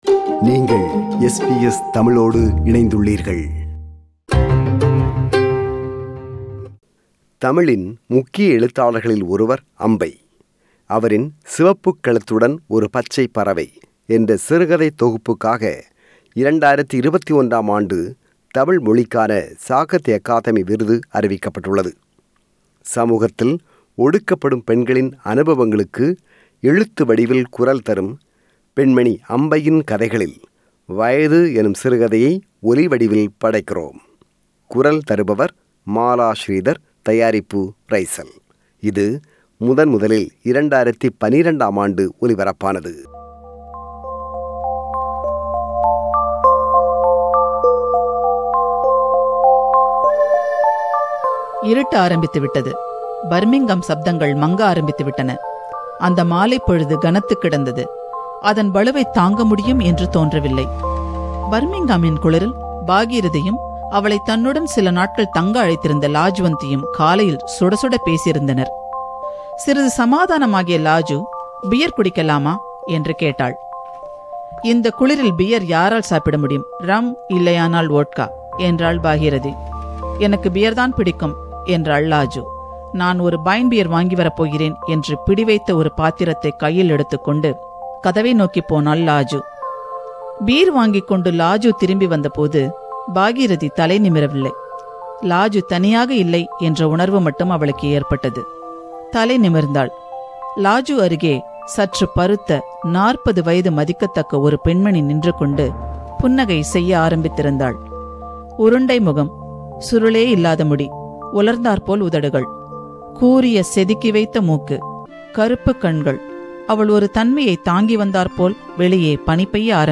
சமூகத்தில் ஒடுக்கப்படும் பெண்களின் அனுபவங்களுக்கு எழுத்து வடிவில் குரல் தரும் அம்பையின் கதைகளில், “வயது” எனும் சிறுகதையை ஒலிவடிவில் படைக்கிறோம்.